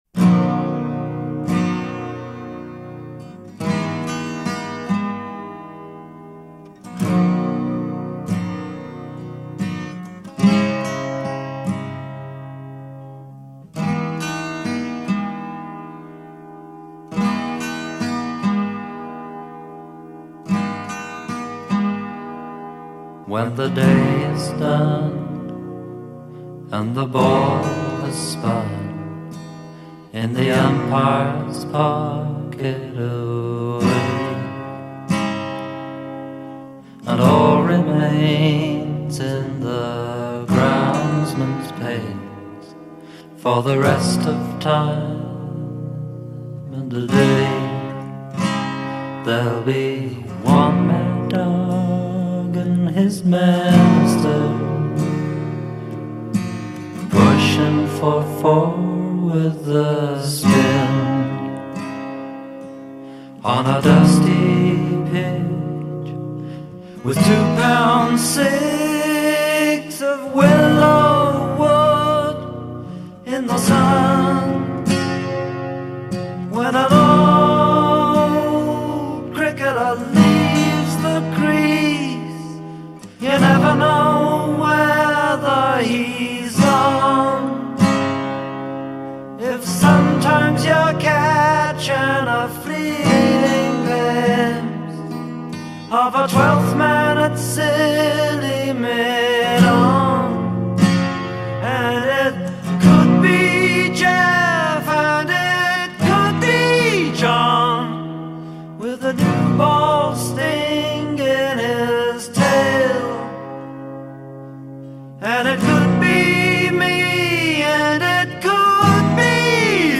wistful and ultra-melancholic
Maybe its the stately brass band that carries him home.